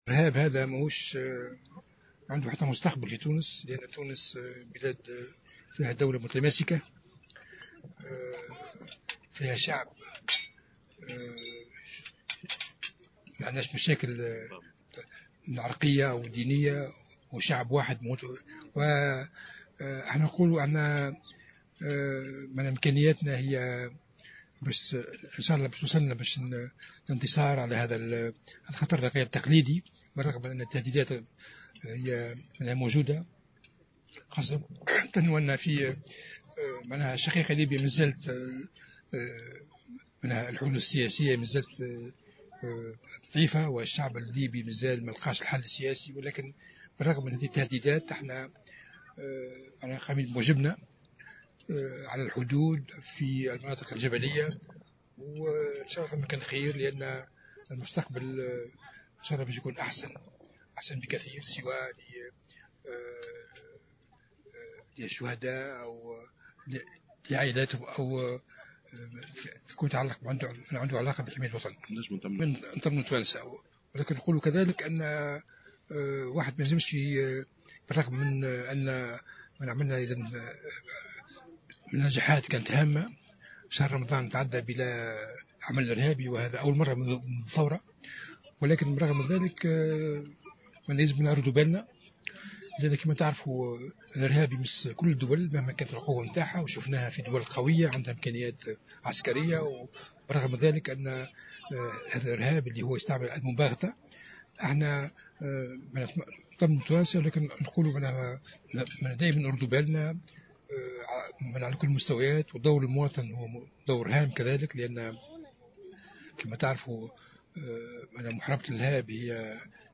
أكد وزير الدفاع فرحات الحرشاني في تصريح لـ "الجوهرة أف أم" اليوم الاثنين أنه لا مستقبل للإرهاب في تونس.
وجاءت تصريحاته على هامش زيارة لمركز الاصطياف بالحمامات لتكريم امهات وأرامل شهداء العمليات الإرهابية من الأمن والجيش الوطنيين.